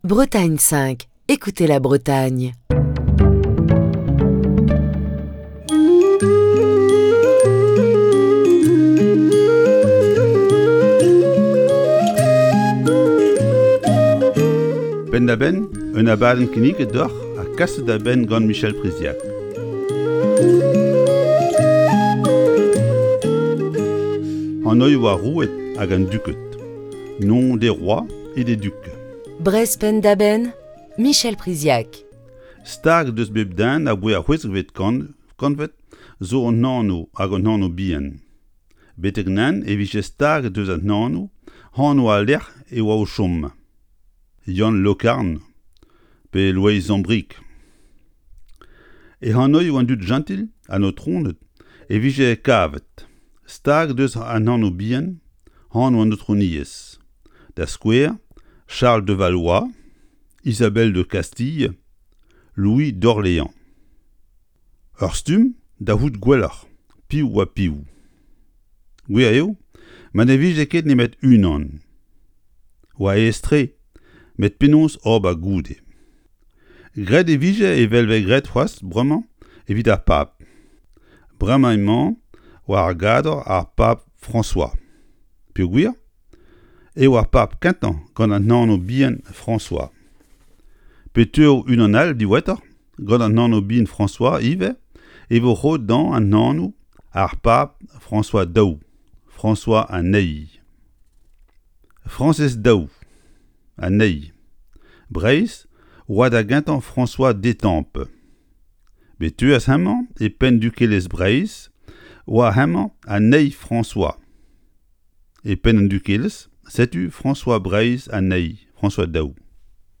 Chronique du 23 septembre 2021.